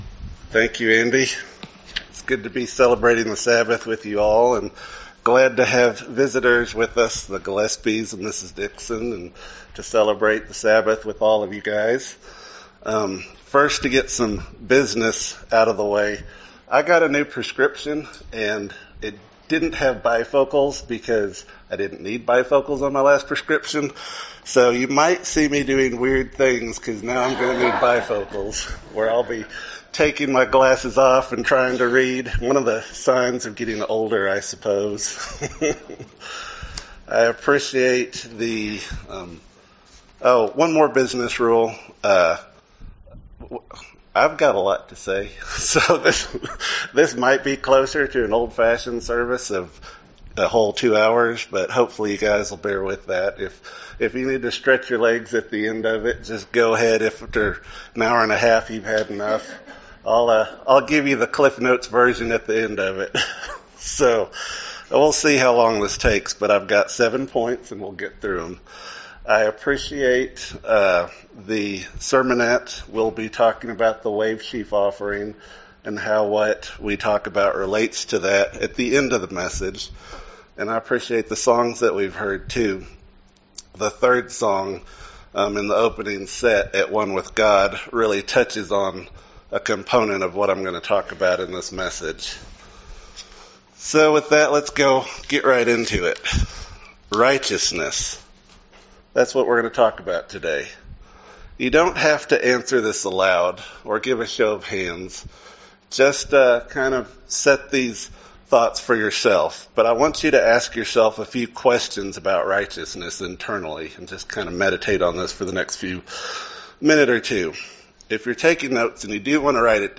This sermon looks at the subject of righteousness in faith by examining 7 principles on the topic. The sermon keys in on Abraham's example. The meaning of this topic is related to the upcoming Feast of Pentecost holy day.